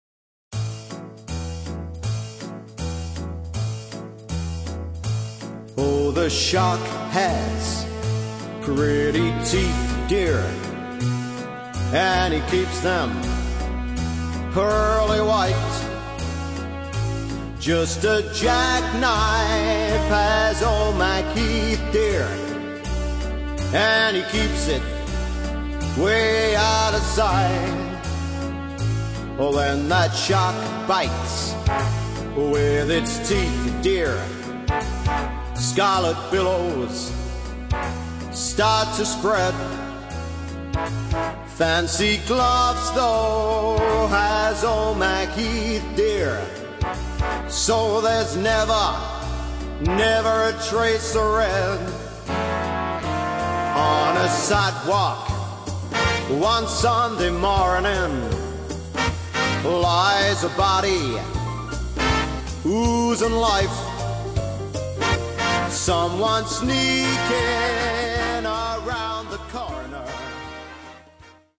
Swing and Rat Pack Singers
it really does sound like a big band backing him.